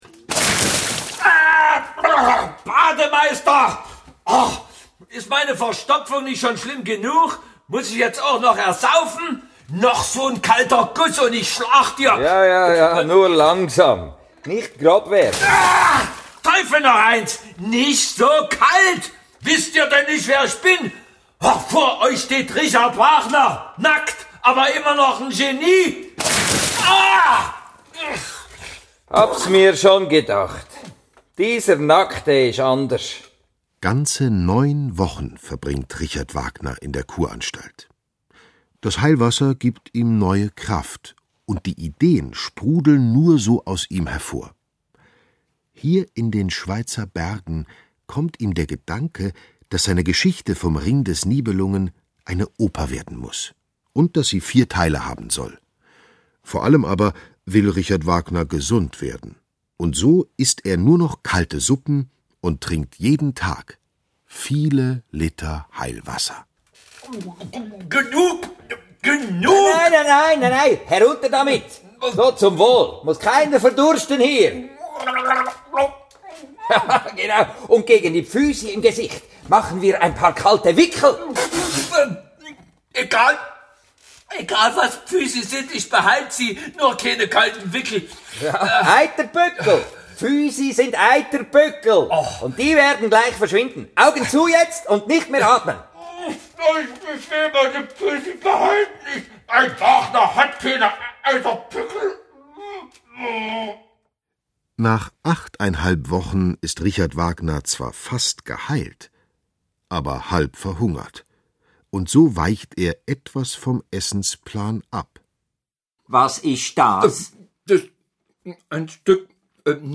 Schlagworte Audio-CD, Kassette / Kinder- und Jugendbücher/Sachbücher, Sachbilderbücher/Kunst • Hörbuch für Kinder/Jugendliche • Hörbuch für Kinder/Jugendliche (Audio-CD) • Klassik-CDs Kinder-CDs • Klassische Musik • Ring der Nibelungen • Wagner, Richard • Wagner, Richard; Kindersachbuch/Jugendsachbuch • Wagner, Richard; Kindersachbuch/Jugendsachbuch (Audio-CDs)